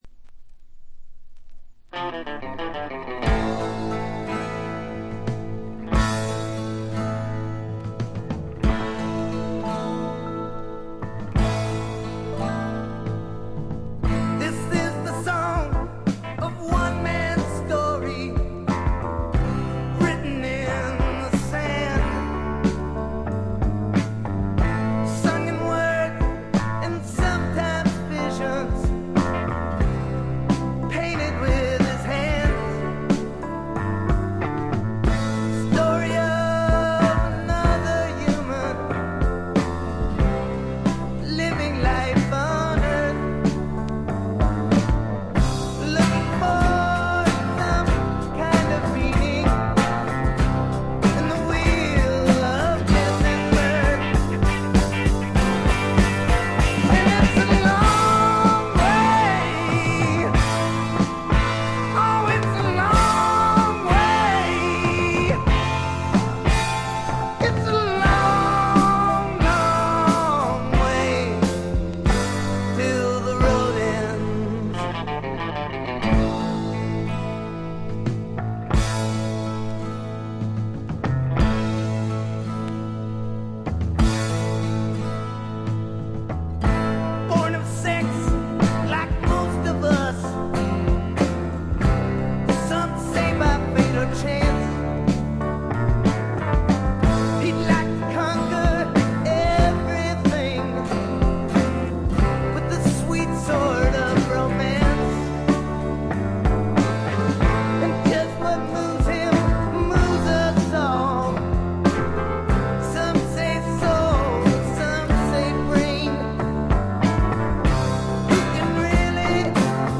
わずかなノイズ感のみ。
渋みのあるヴォーカルも味わい深い88点作品。
試聴曲は現品からの取り込み音源です。